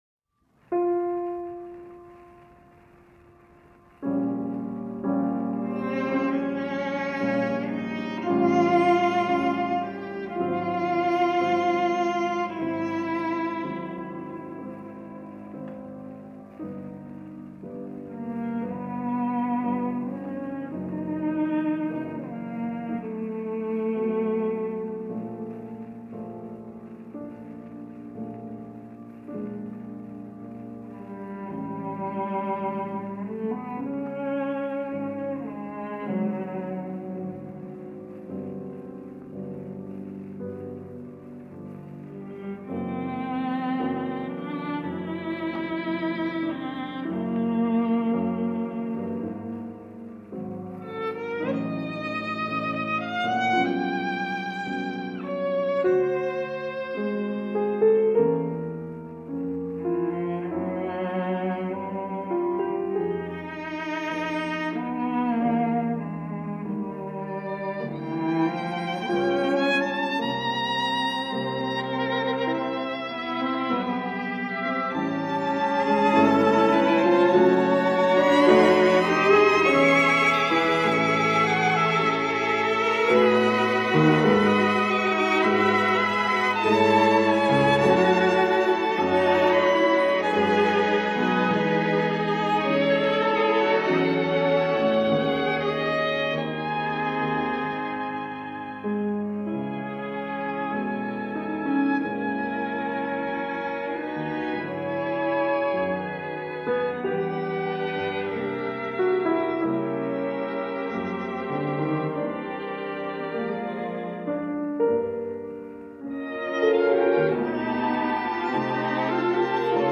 first violin
second violin
viola
cello